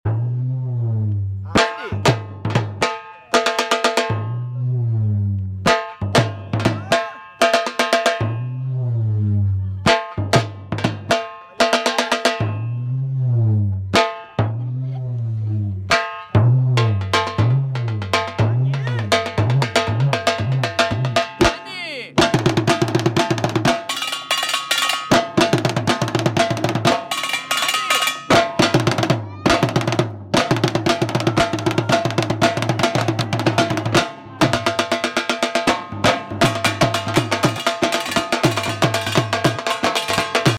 beautiful Dhol beat